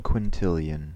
Ääntäminen
Synonyymit quadrillion Ääntäminen US Tuntematon aksentti: IPA : /kwɪn.ˈtɪl.jən/ Haettu sana löytyi näillä lähdekielillä: englanti Käännöksiä ei löytynyt valitulle kohdekielelle.